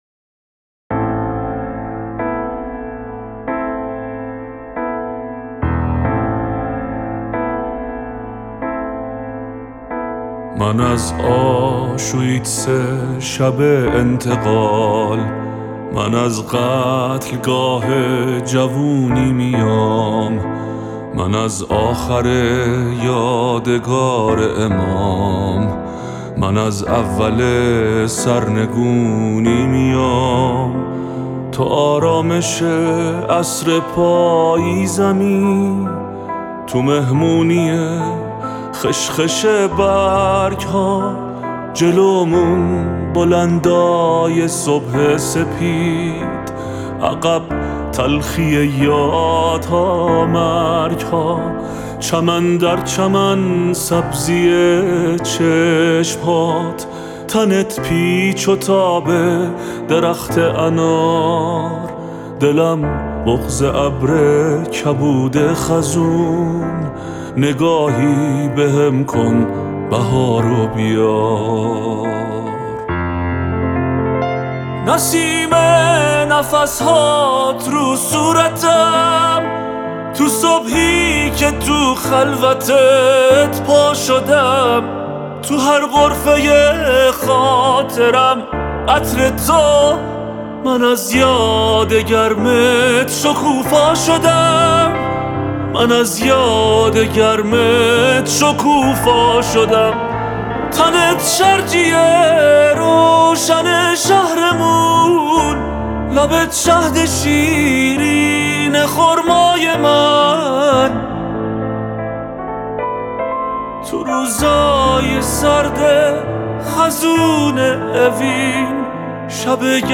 پیانو